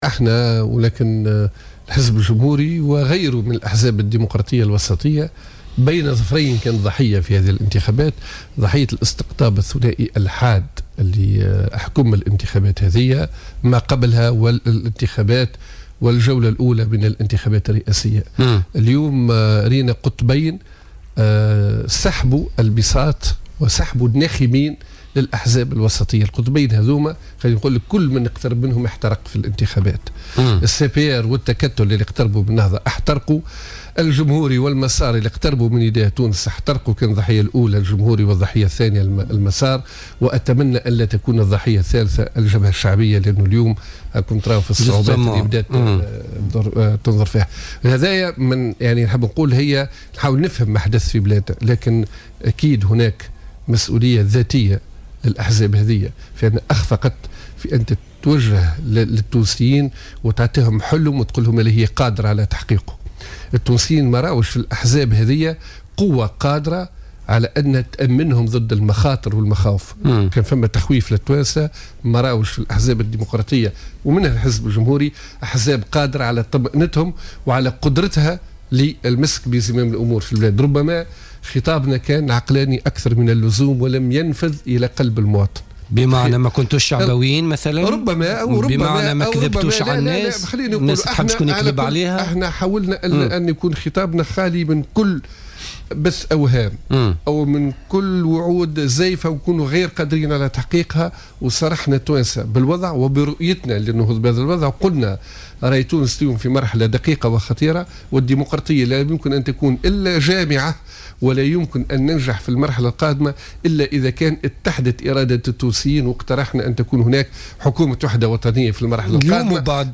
علّق عصام الشابي، الناطق الرسمي باسم الحزب الجمهوري لدى استضافته اليوم في برنامج "بوليتيكا"على نتائج الانتخابات التشريعية والدورة الأولى للانتخابات الرئاسية.